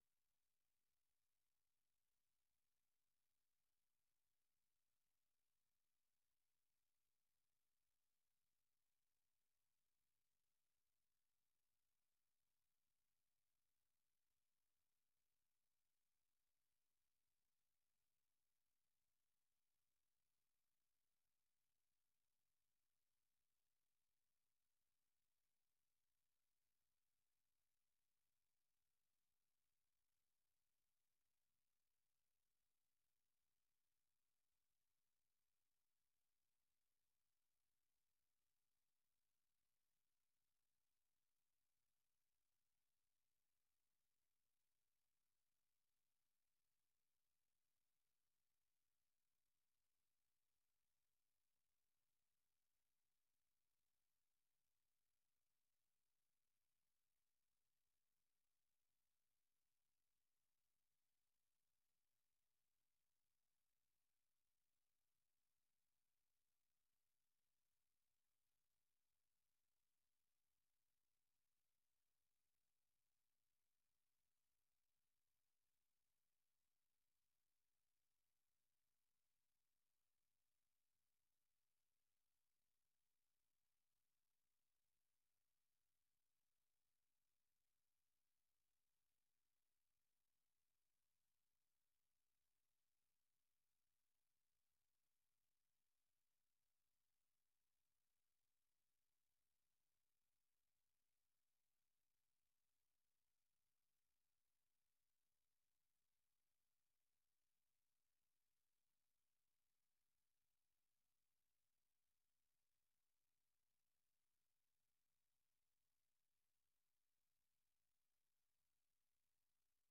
Murisanga (1400-1500 UTC): Murisanga itumira umutumirwa, cyangwa abatumirwa kugirango baganire n'abakunzi ba Radiyo Ijwi ry'Amerika. Aha duha ijambo abantu bifuza kuganira n'abatumirwa bacu, batanga ibisobanuro ku bibazo binyuranye bireba ubuzima bw'abantu.